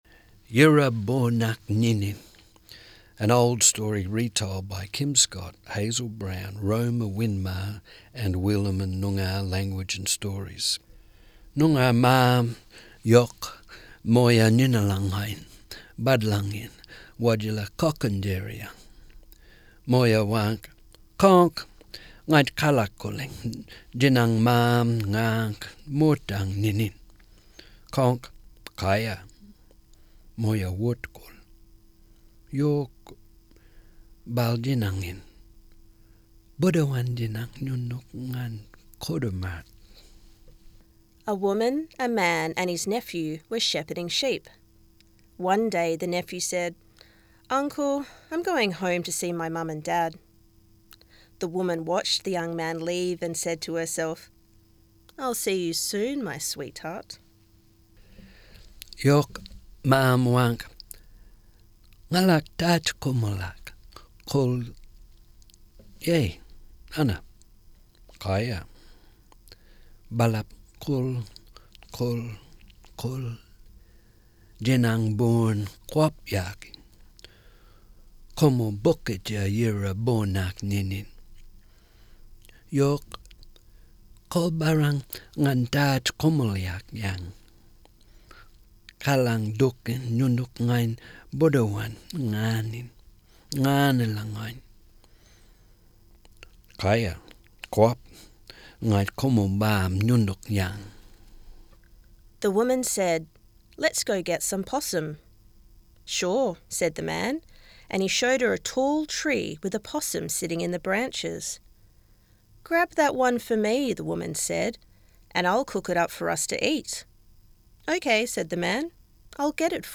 Yira-Boornak-Nyininy-Reading-Edit.mp3